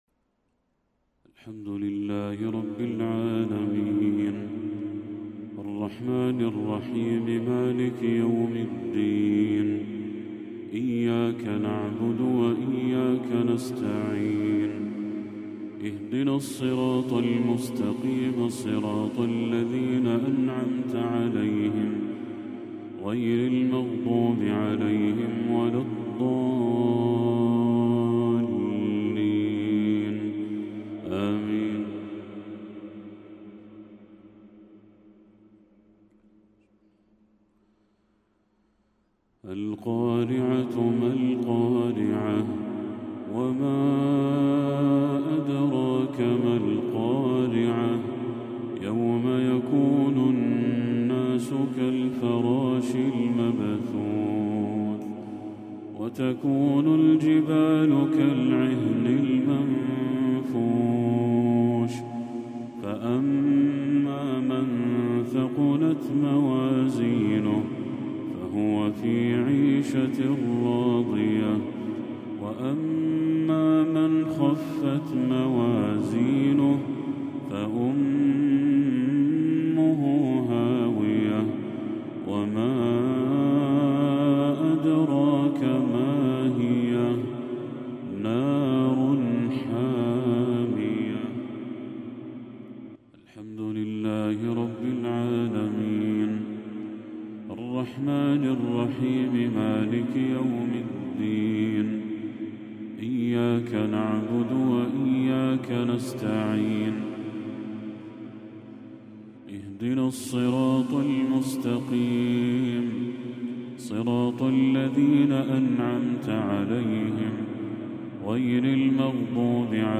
تلاوة للشيخ بدر التركي سورتي القارعة والتكاثر | مغرب 24 ربيع الأول 1446 > 1446هـ > تلاوات الشيخ بدر التركي > المزيد - تلاوات الحرمين